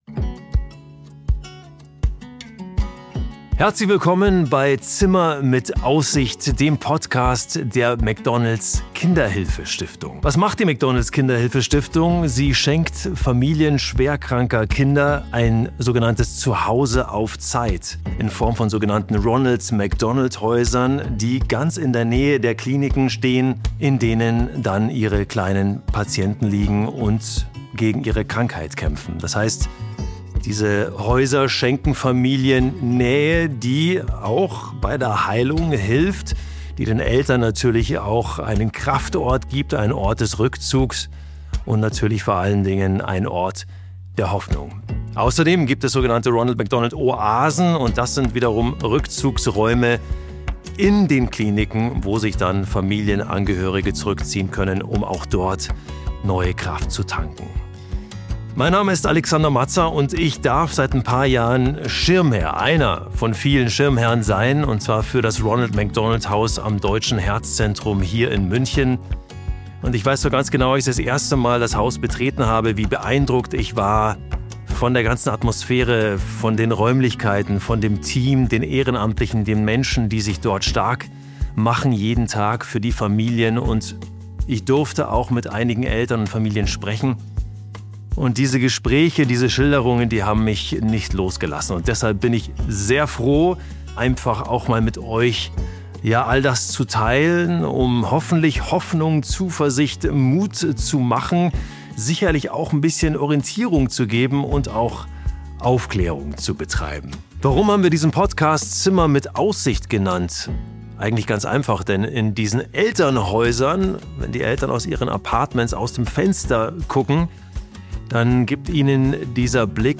Trailer
In diesem Podcast spricht Gastgeber Alexander Mazza mit Eltern,